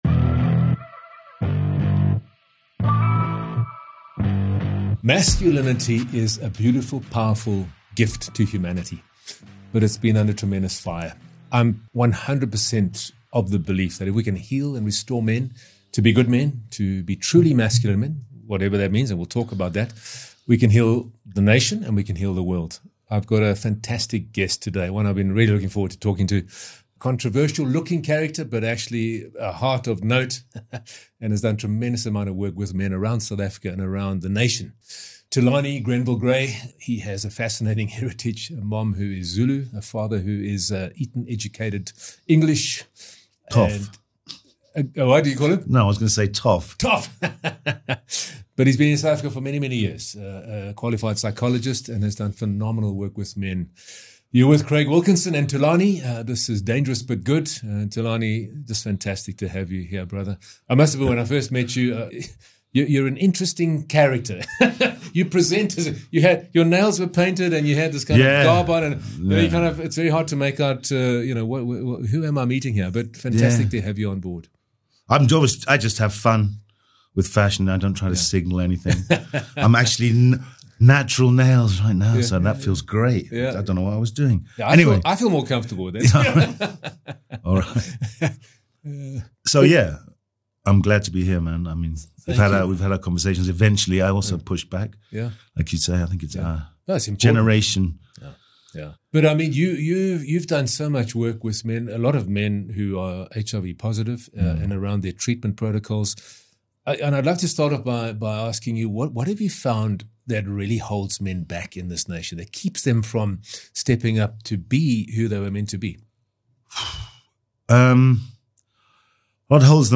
This frank and sometimes uncomfortable conversation offers a rare glimpse into the male psyche - how early experiences shape identity, how cultural expectations suppress emotion, and how unspoken pain often drives destructive behaviour.